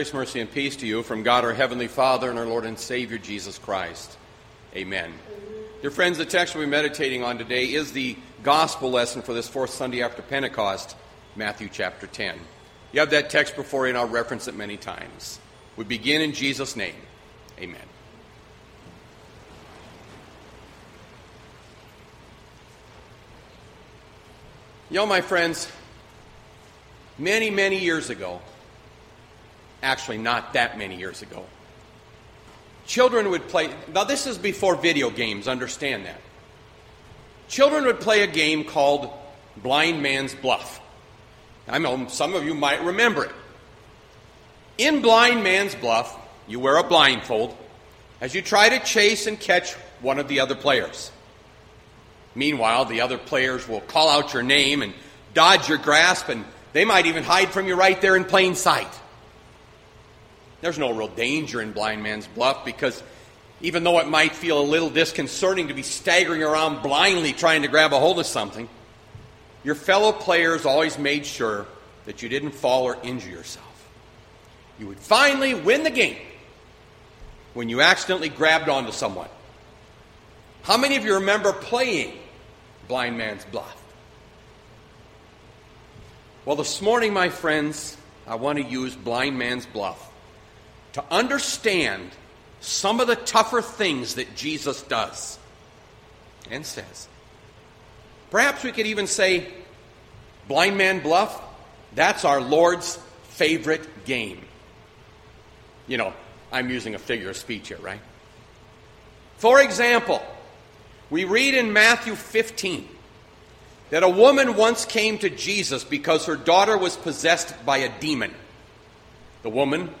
Bethlehem Lutheran Church, Mason City, Iowa - Sermon Archive Jun 28, 2020